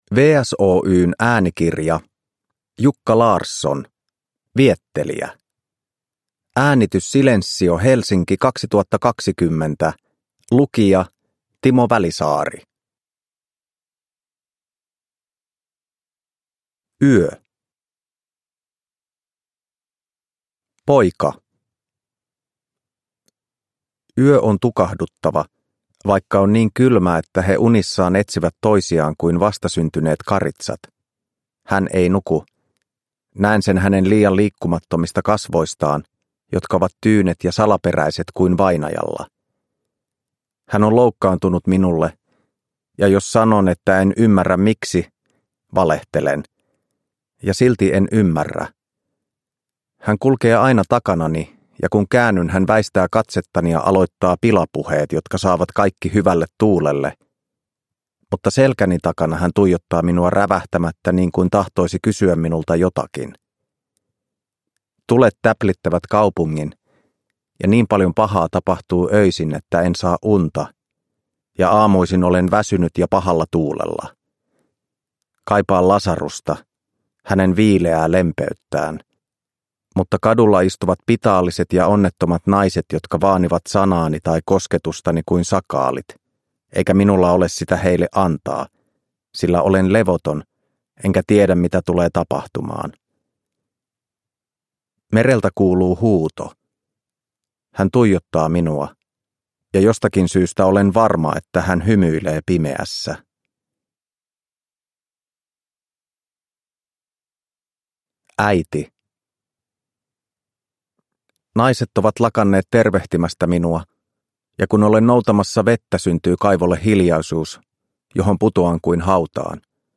Viettelijä – Ljudbok – Laddas ner